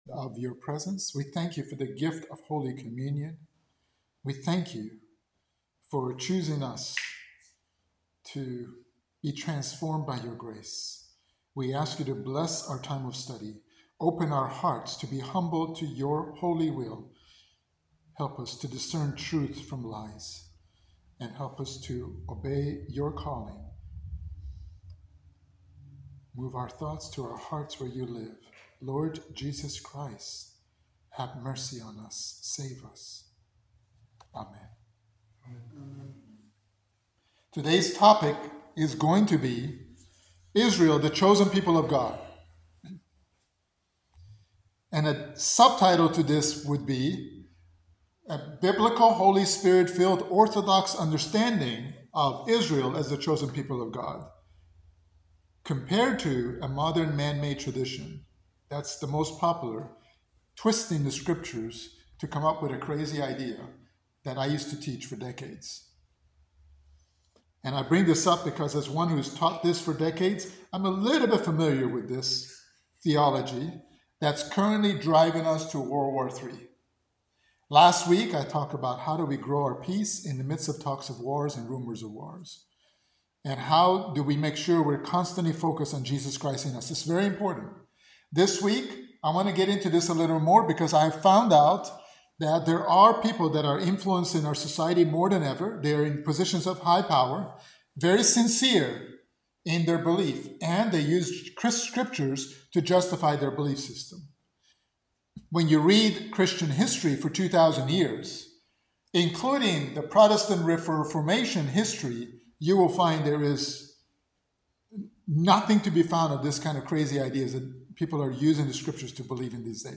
In this Orthodox Bible and adult study